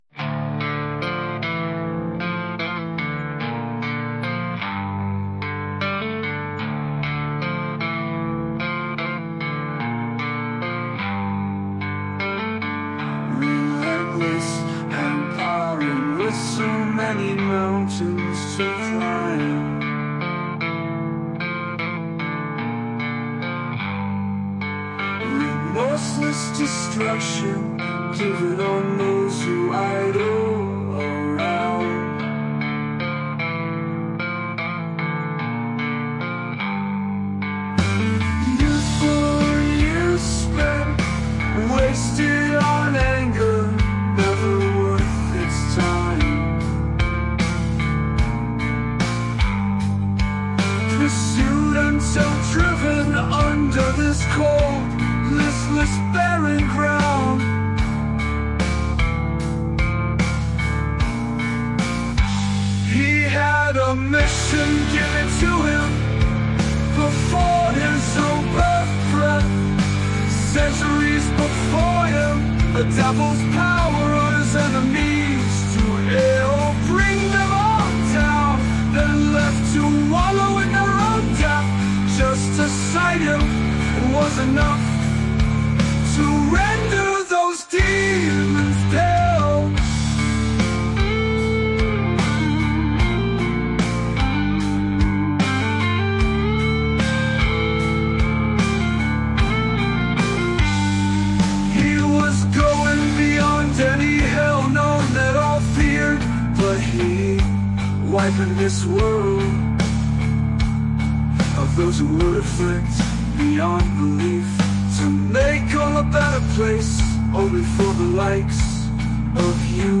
Thanks for the vocals.